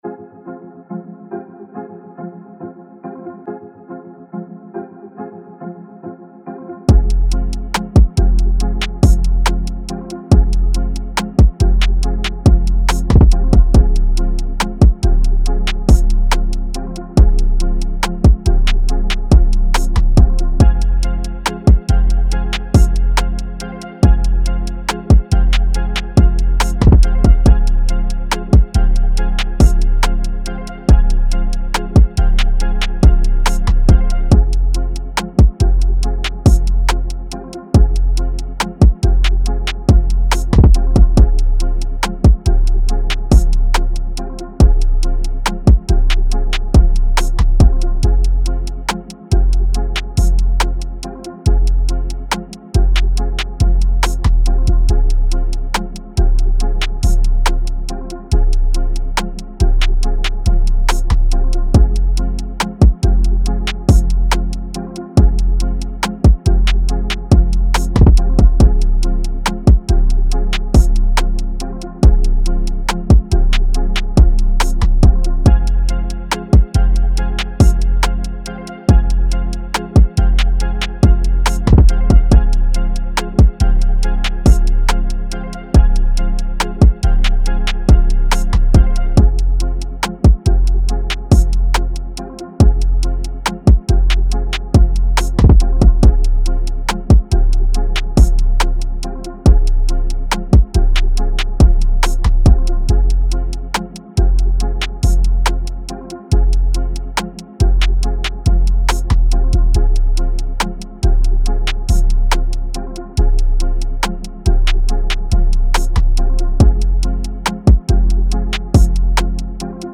Hip Hop, R&B
F Minor